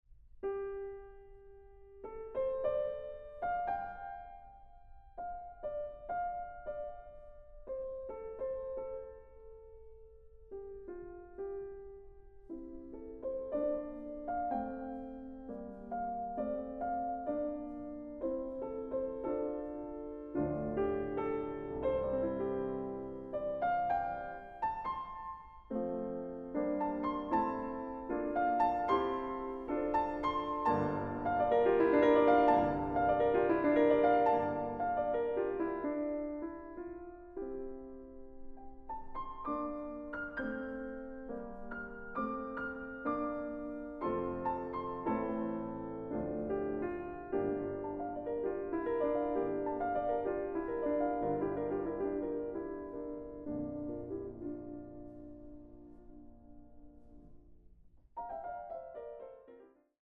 Piano
Recording: Mendelssohnsaal, Gewandhaus Leipzig